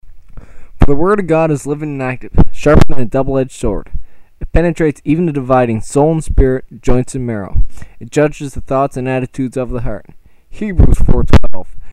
WARNING:  these MP3 files are not of the highest quality.  they have been recorded on a 7 dollar microphone bought at staples.
There is slight crackeling in some of the tracks.